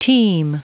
Prononciation du mot teem en anglais (fichier audio)
Prononciation du mot : teem